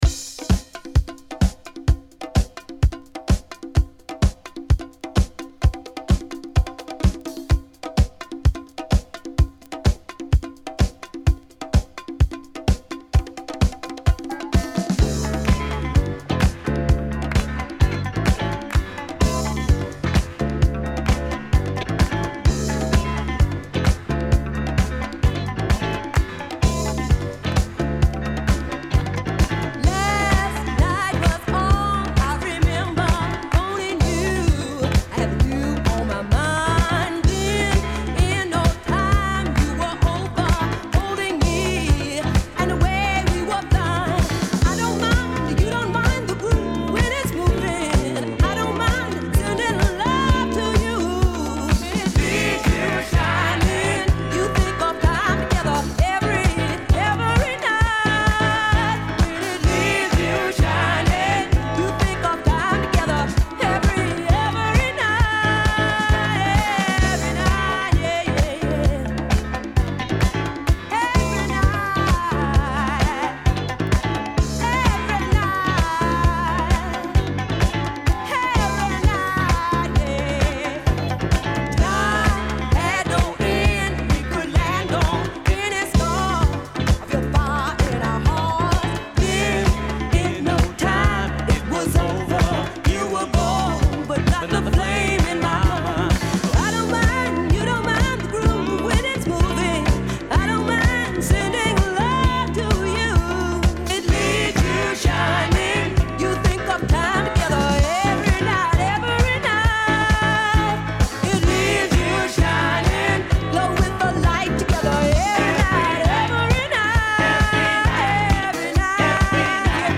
伸びやかなヴォーカルが冴えるディスコブギー